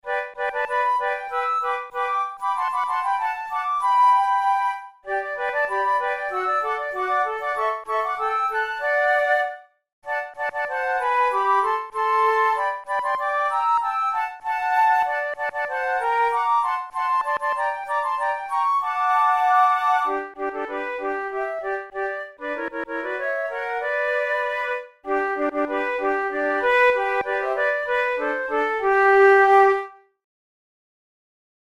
arranged for two flutes
Categories: Opera excerpts Romantic Difficulty: intermediate